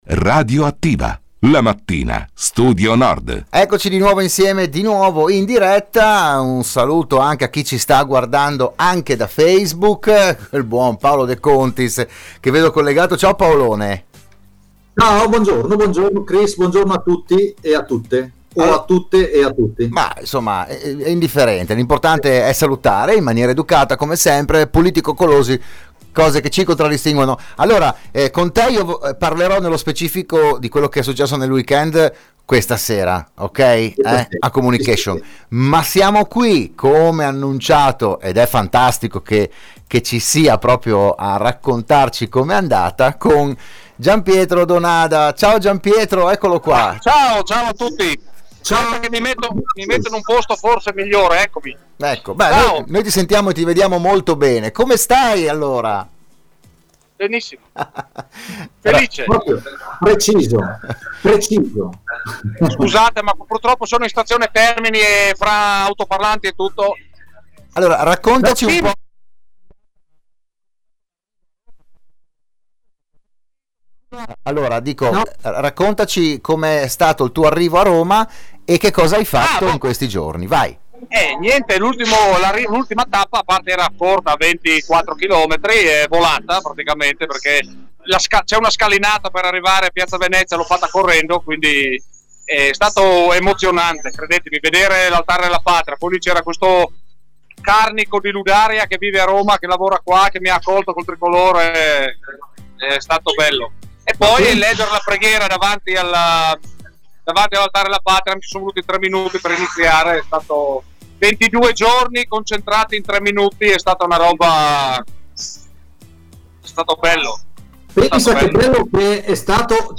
L'audio e il video del collegamento con la capitale durante la trasmissione di Radio Studio Nord "RadioAttiva"